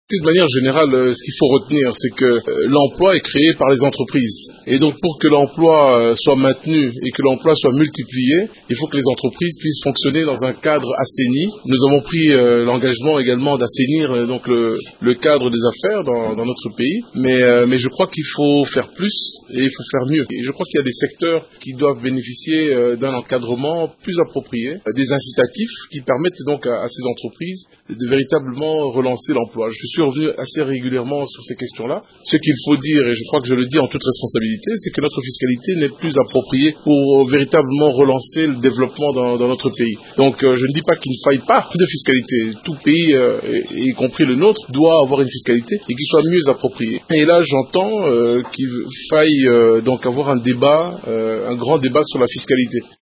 Cliquez ci-dessous pour suivre les propos de Joseph Nzanga Mobutu: